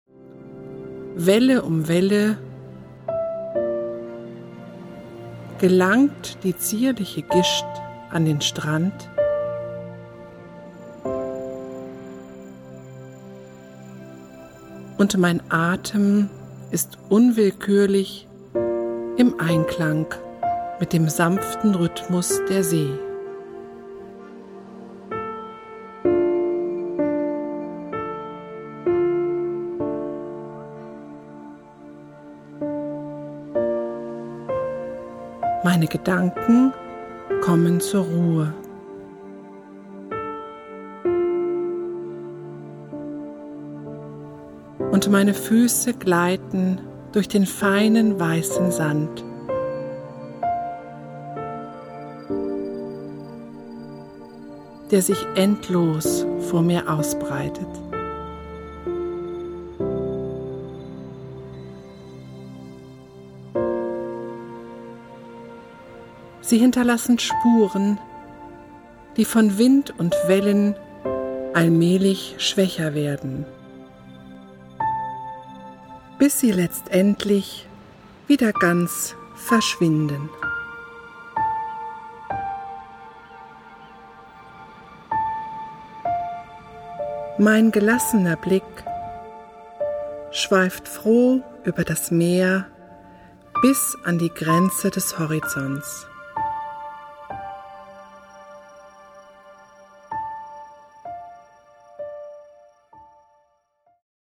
Eine innere Reise zur Ruhe mit entspannenden und bewußtseinserweiternden Texten, untermalt mit Naturgeräuschen und weichsanften Klängen.